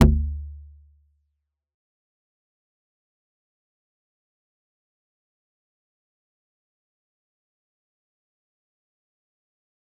G_Kalimba-G1-f.wav